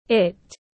Nó tiếng anh gọi là it, phiên âm tiếng anh đọc là /ɪt/.
It /ɪt/